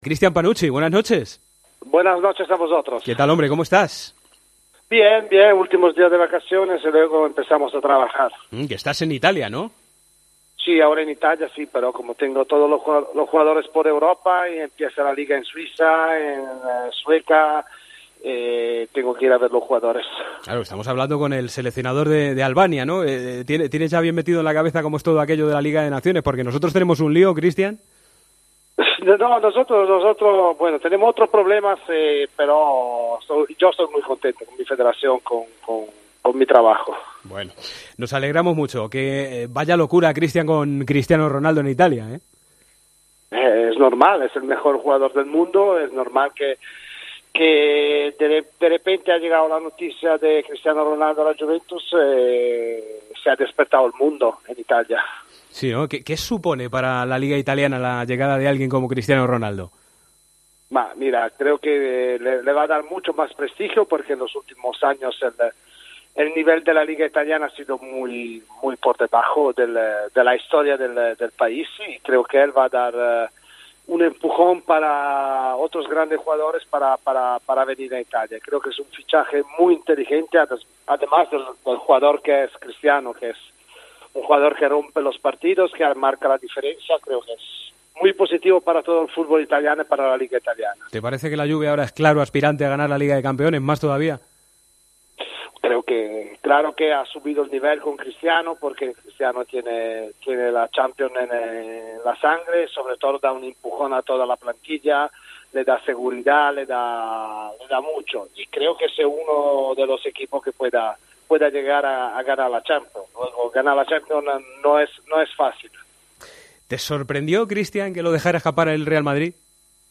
El seleccionador de Albania y exjugador del Real Madrid ha estado en el Partidazo de Cope y ha analizado lo que supone el fichaje de Cristiano Ronaldo para la Juventus y la Serie A italiana.